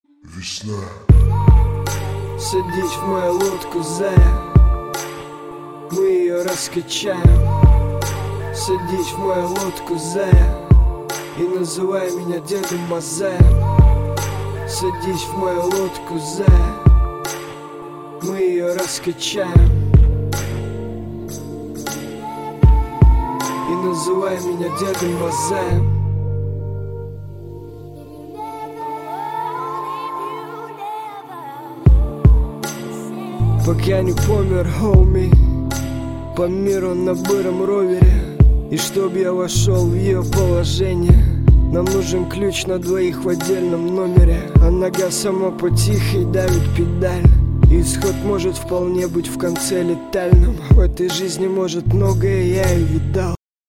• Качество: 128, Stereo
спокойные